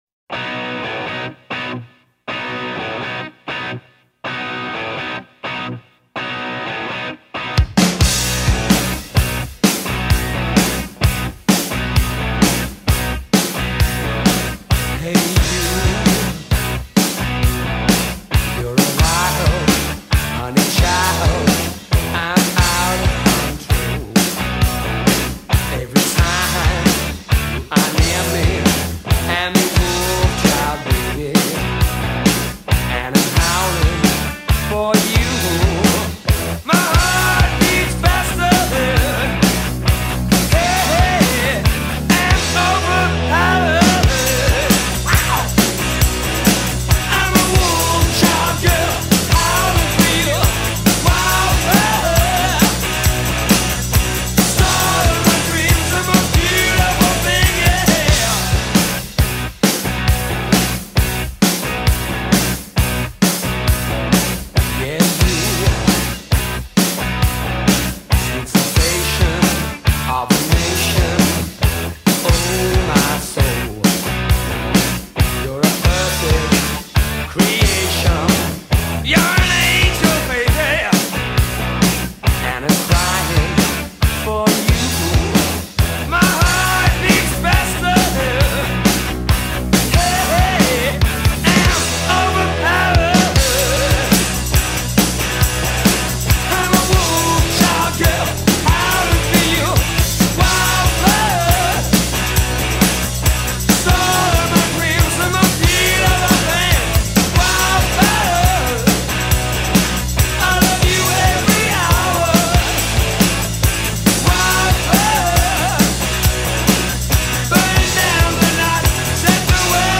rockers